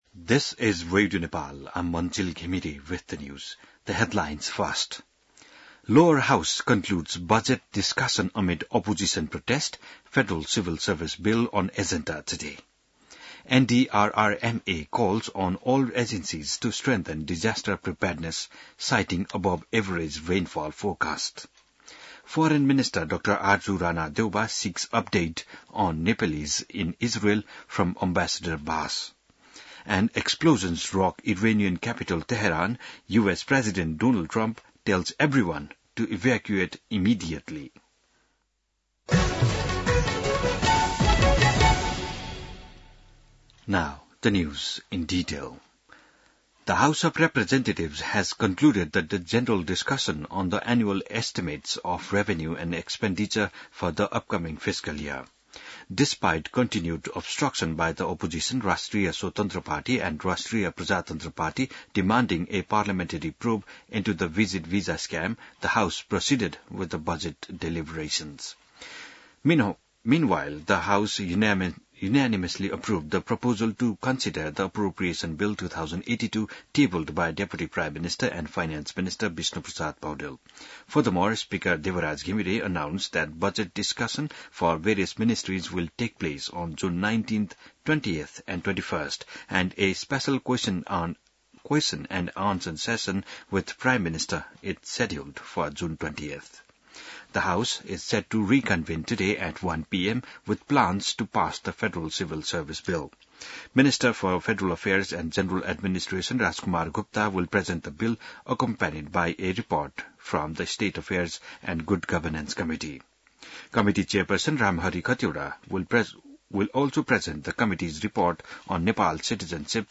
बिहान ८ बजेको अङ्ग्रेजी समाचार : ३ असार , २०८२